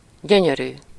Ääntäminen
IPA: /sxoːn/